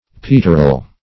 peterel - definition of peterel - synonyms, pronunciation, spelling from Free Dictionary
peterel - definition of peterel - synonyms, pronunciation, spelling from Free Dictionary Search Result for " peterel" : The Collaborative International Dictionary of English v.0.48: Peterel \Pet"er*el\ (p[e^]t"[~e]*[e^]l), n. (Zool.)